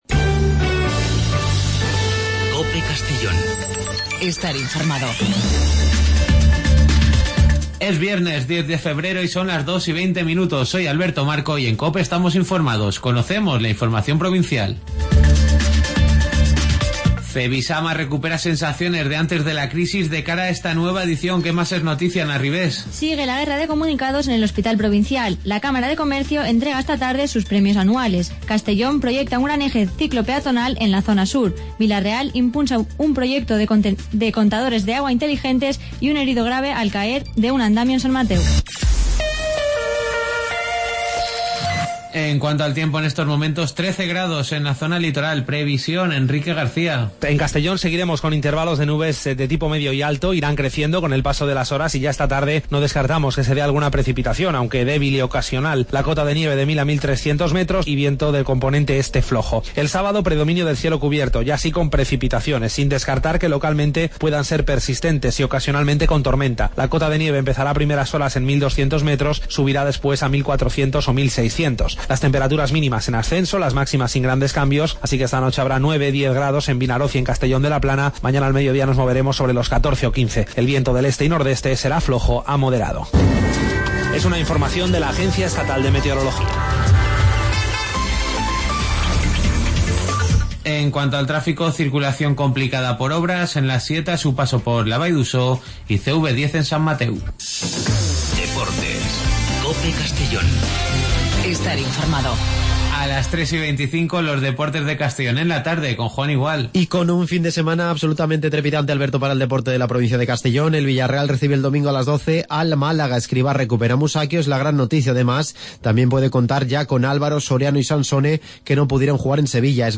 Las noticias del día de 14:20 a 14:30 en Informativo Mediodía COPE en Castellón.